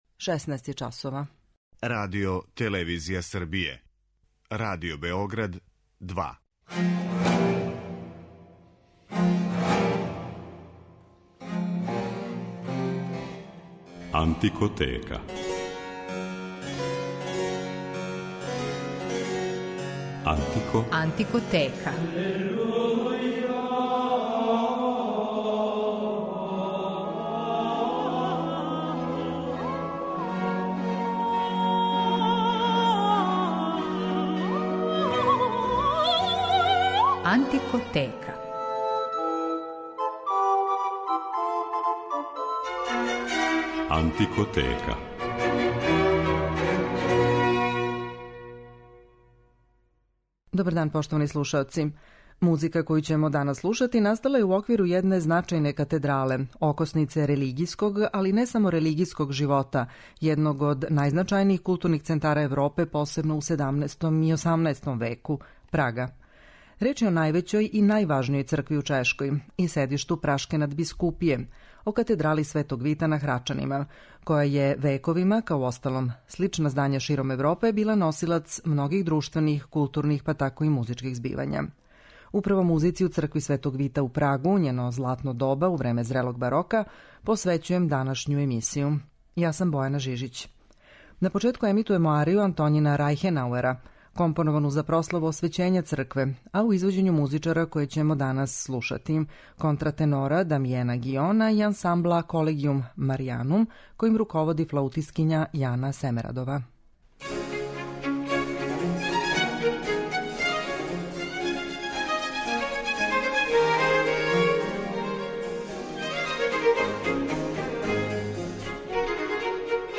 води слушаоце у свет ране музике и прати делатност уметника специјализованих за ову област који свирају на инструментима из епохе или њиховим копијама.